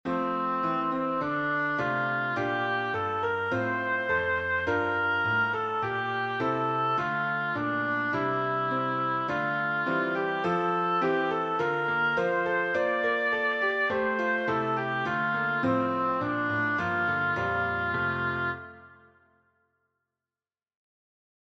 Hymn composed by